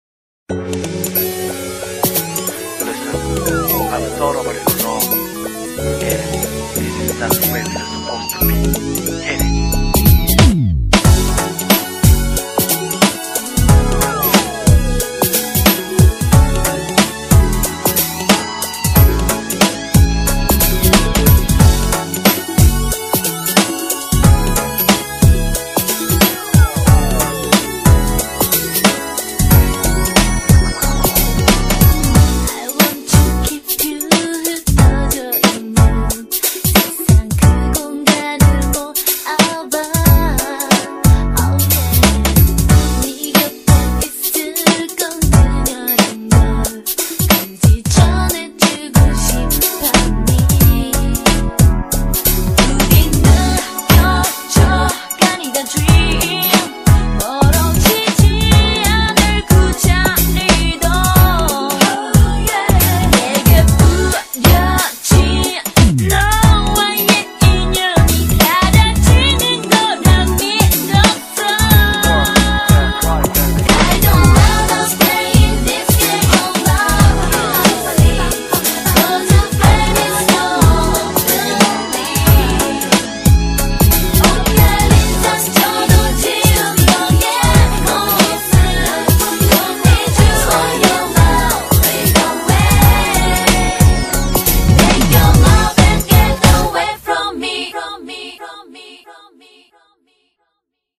BPM91--1
Audio QualityPerfect (High Quality)